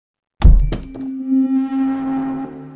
sfx_mic_drop.mp3